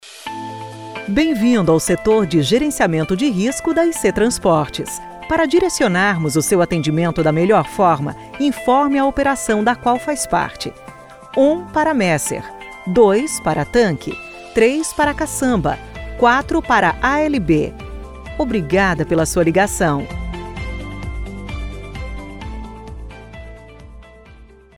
Atendimento Digital - Ura: